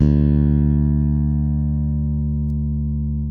ALEM FING D2.wav